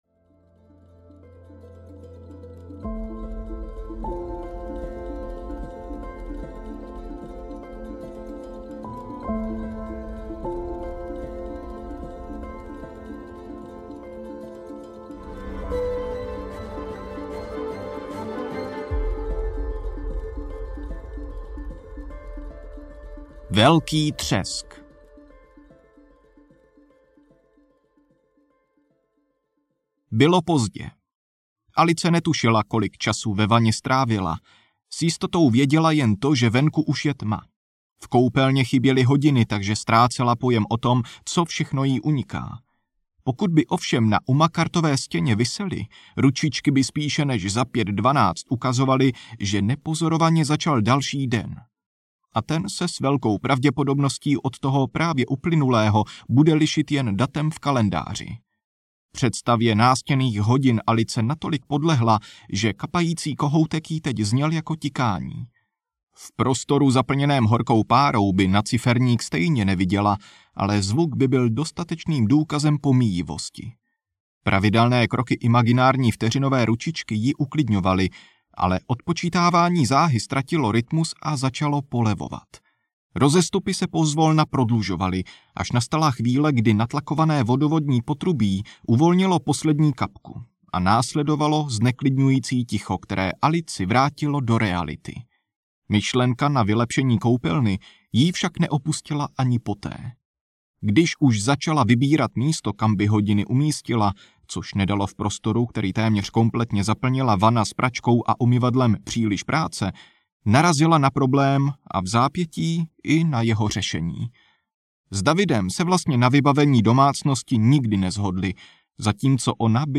Rekviem za Pluto audiokniha
Ukázka z knihy